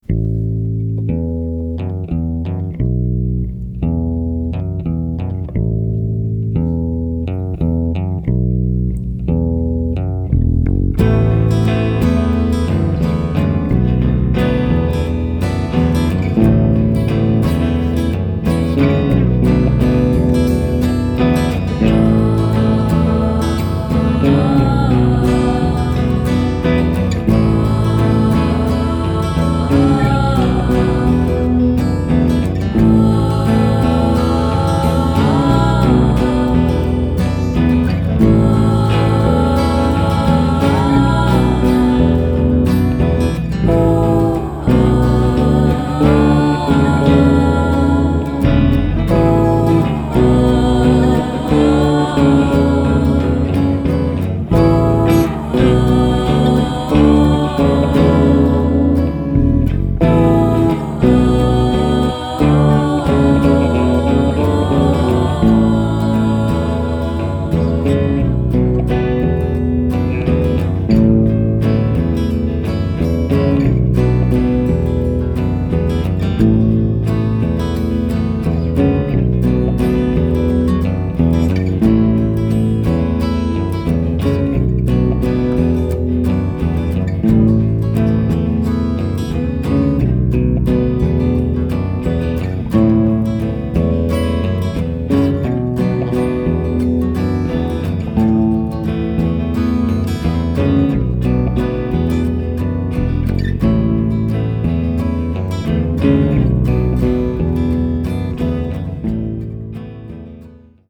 (ethereal and sensual)